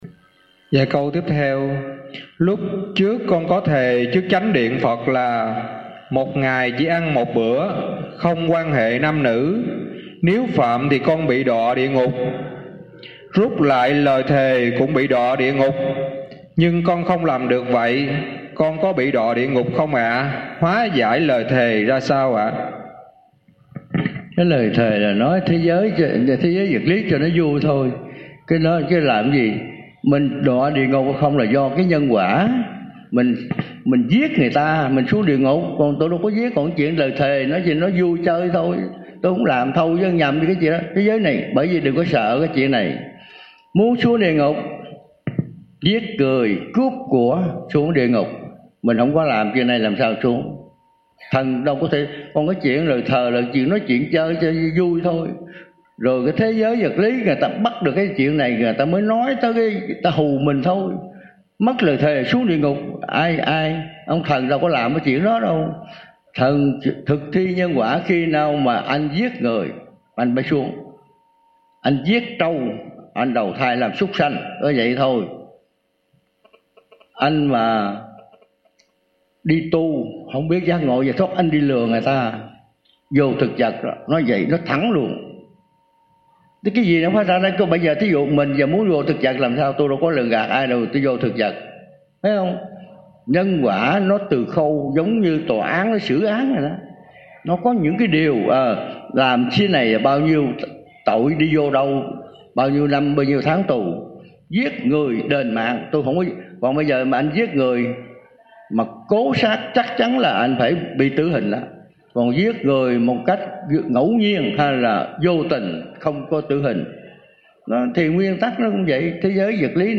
Trò hỏi:
Thầy trả lời: